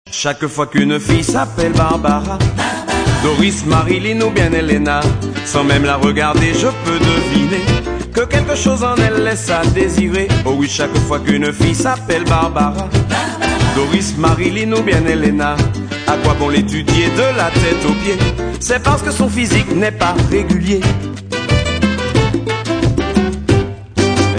Guitare
Saxes
Contrebasse